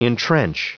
Prononciation du mot entrench en anglais (fichier audio)
Prononciation du mot : entrench